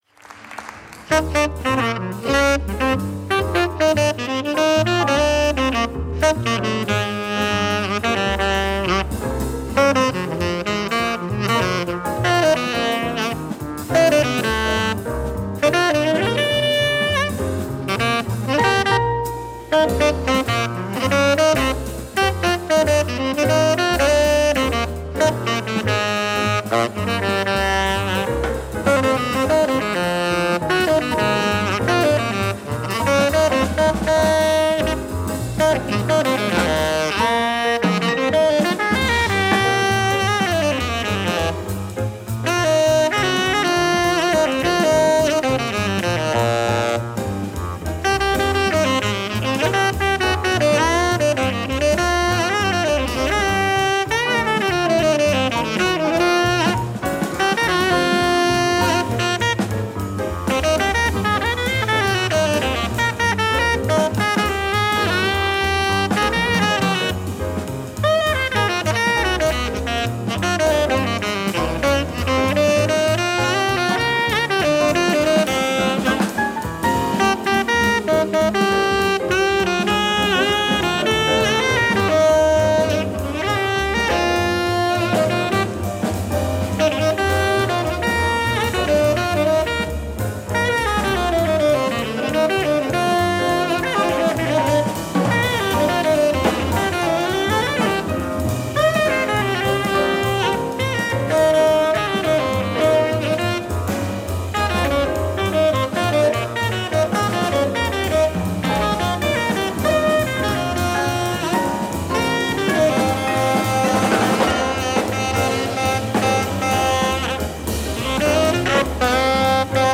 Recorded at Studio 154, Maison de la Radio.
piano
bass
drums